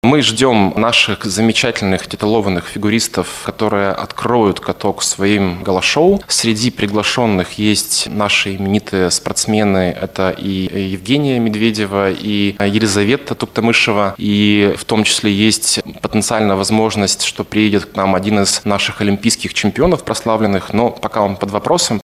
на пресс-конференции ТАСС-Урал